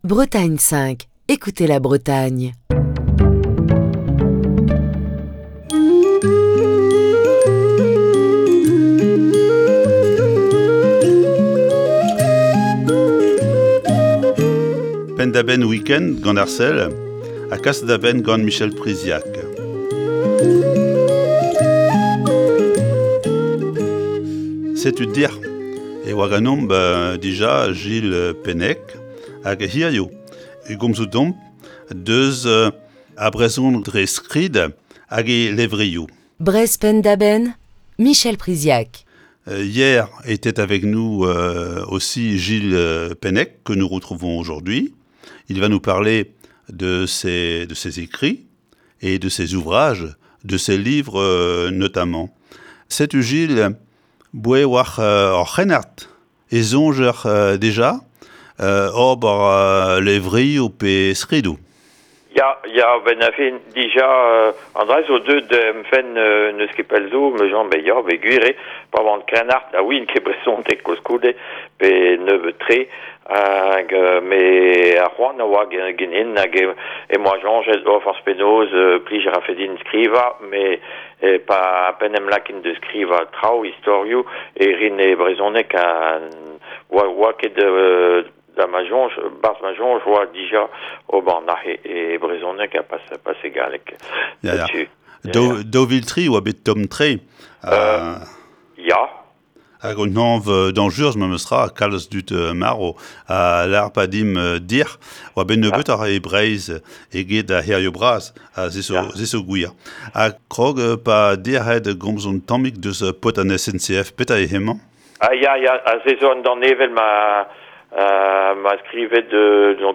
Émission du 17 octobre 2021.